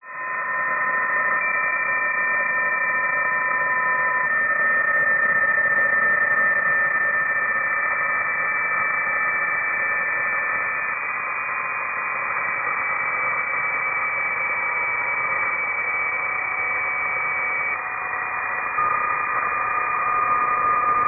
Here are the WAV files he generated with Doppler changing at
4 Hz per second.